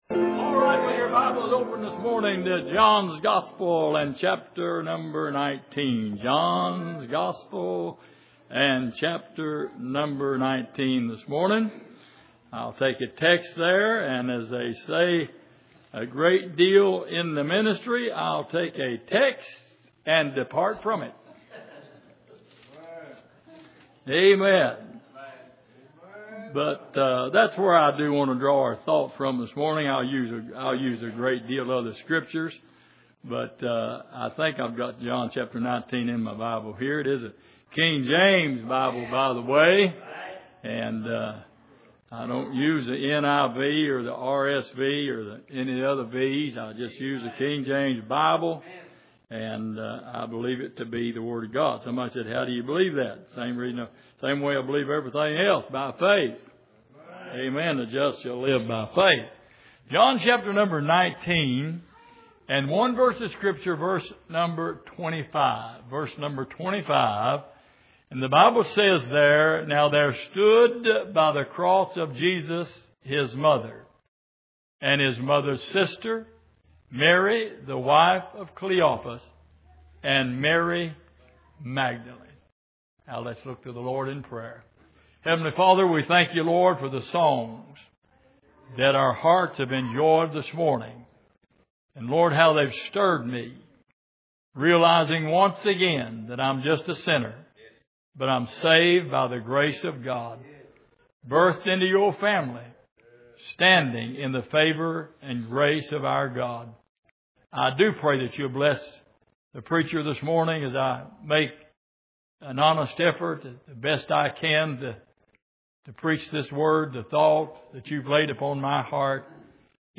John 19:25 Service: Sunday Morning The Pain Of Heartache « The Awakening Of The Soul